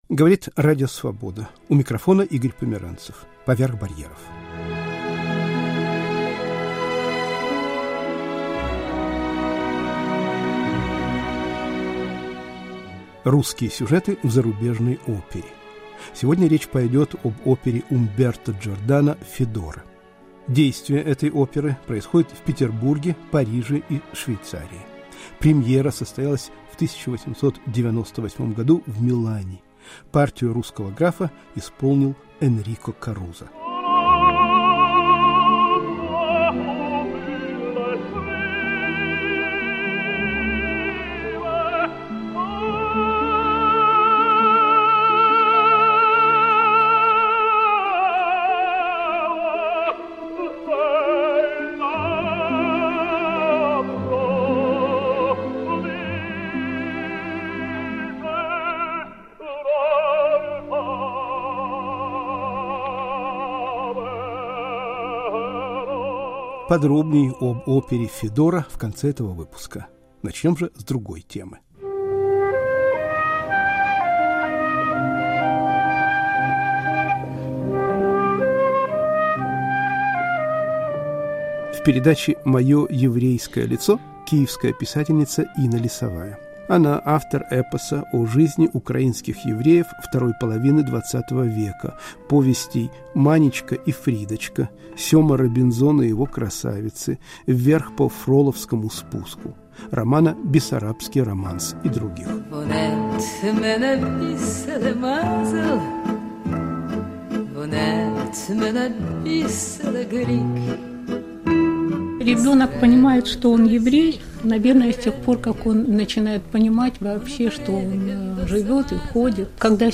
«Заметки из Зазеркалья». Радиоочерк Романа Тименчика *** Русские сюжеты в зарубежной опере. «Федора» Умберто Джордано